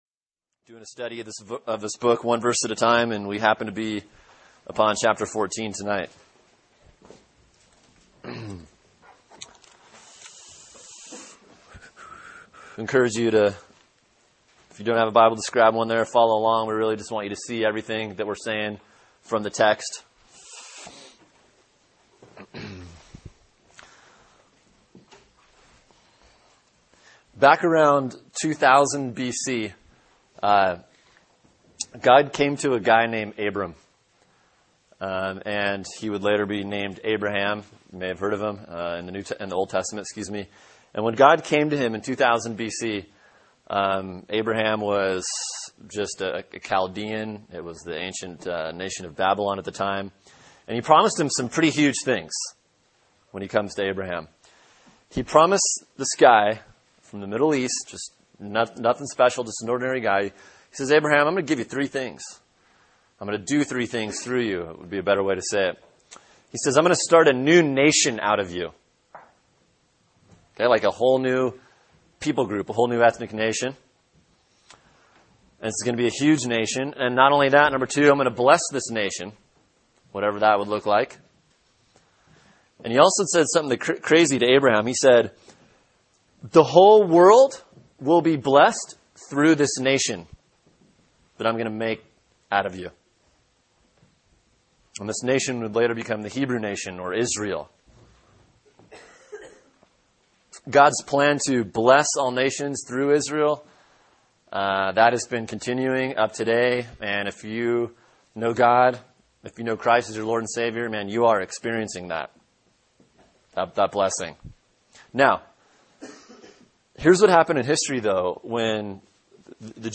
Sermon: 1 Corinthians 14:1-12 [03/06/2011] | Cornerstone Church - Jackson Hole